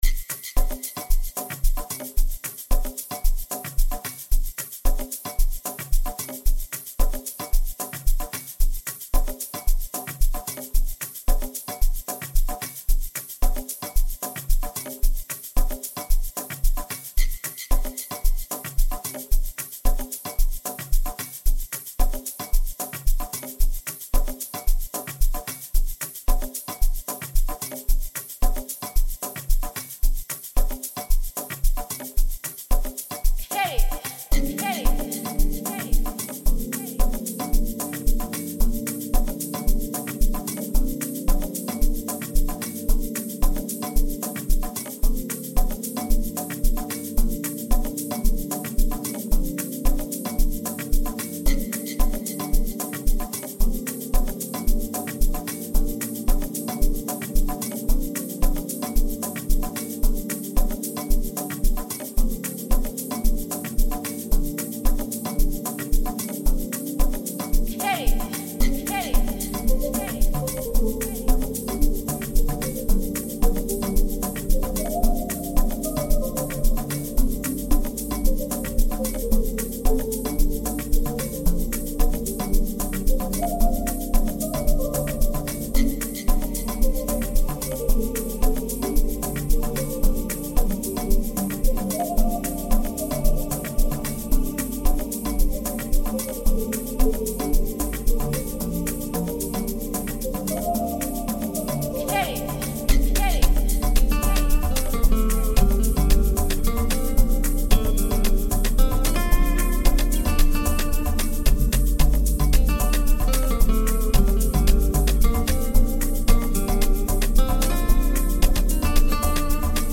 kwaito-piano vibes